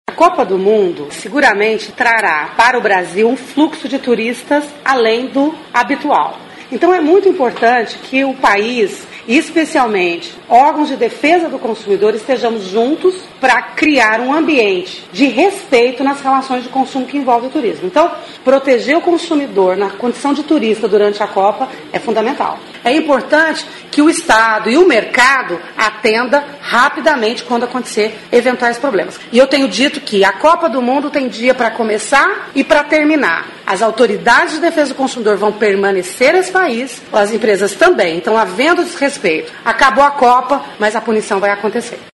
aqui e ouça comentário em que a secretária Juliana Pereira defende união de esforços pelo rápido atendimento de demandas.